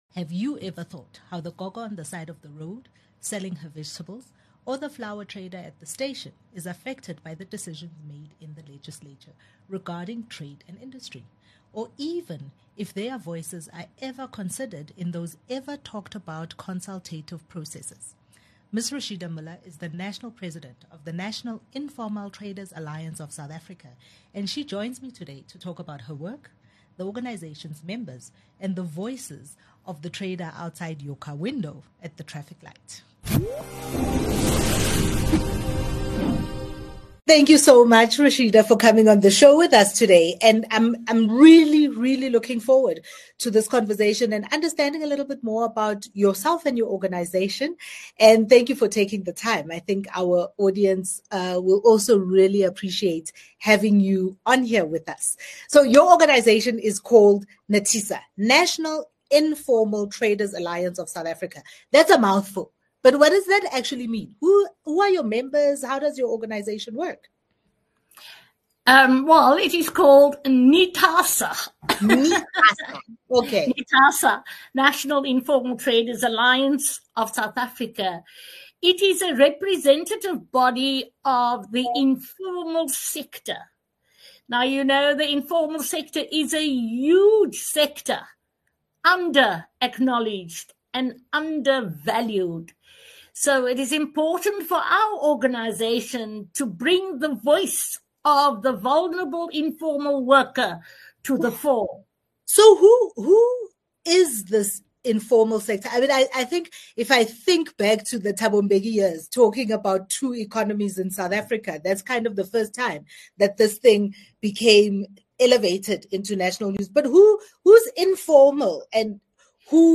1 Exclusive interview